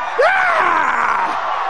Howard Dean really blew his top this time.
deanyell.wav